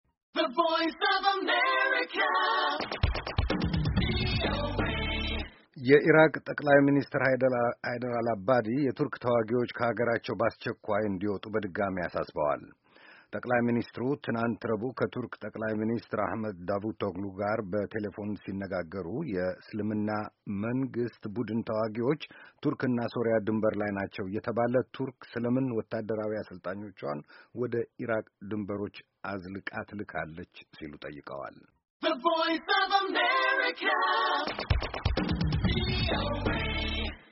የቱርክ የጦር ሓይሎች ከኢራቅ በአስቸኩዋይ መውጣት አለባቸው በማለት የኢራቅ ጠቅላይ ሚኒስትር ጥሪ አቅርበዋል። የዜና ዘገባችንን ከዚህ በታች ካለው የድምጽ ፋይል ያዳምጡ።